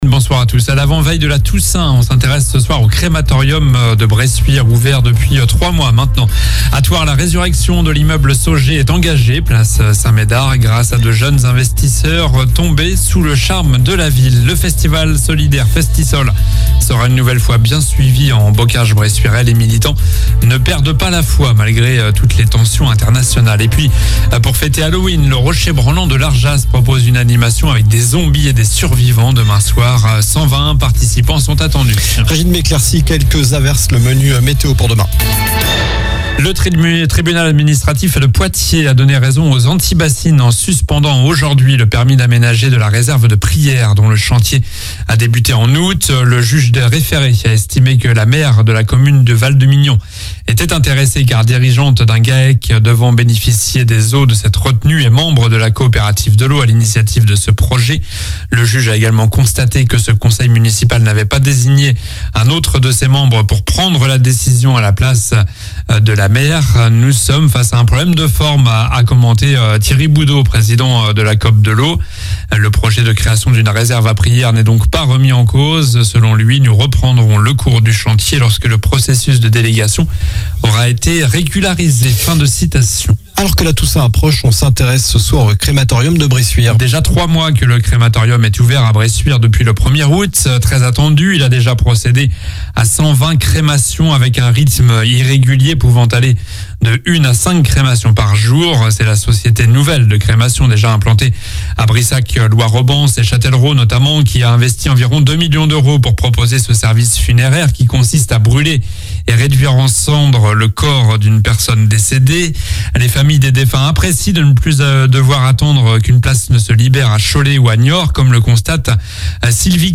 Journal du lundi 30 octobre (soir)